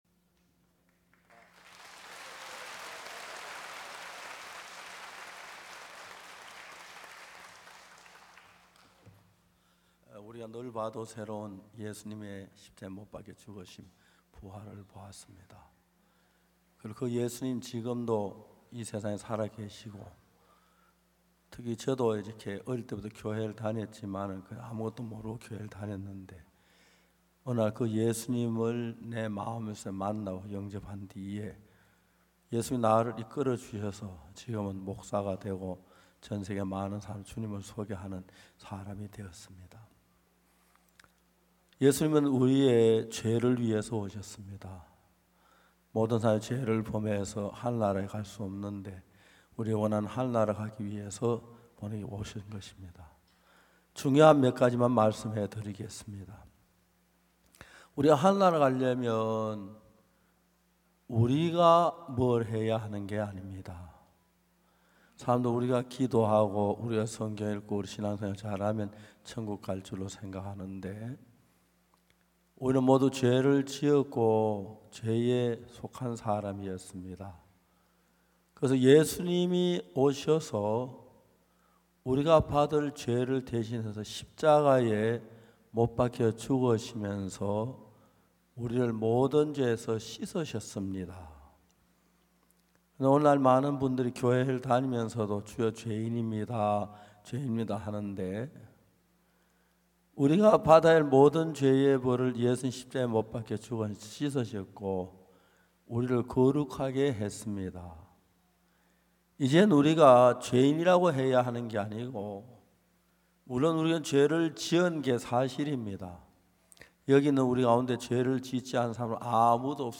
GoodNewsTV Program 2025 전반기 인천성경세미나 #4 의롭다 하심을 얻은자 되었느니라